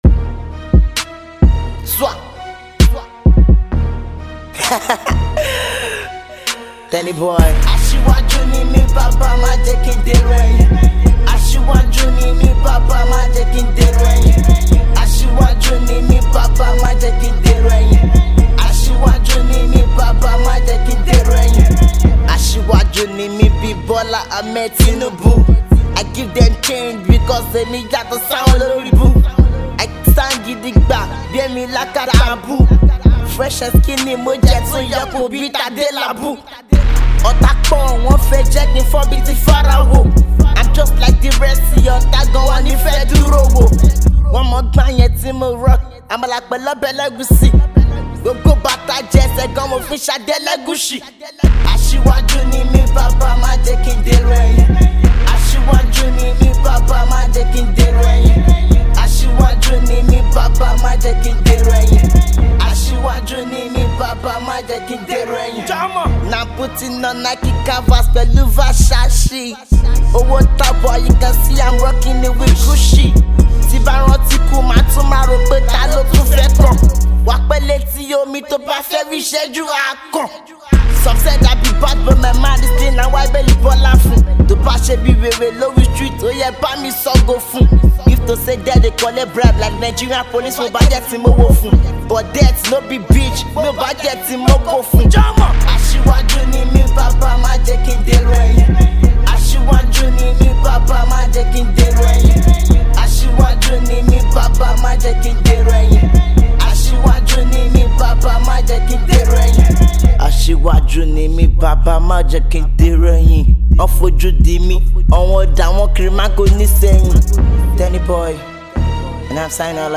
Hip-HopSnap MusicYoruba Music
its a Wild Trap song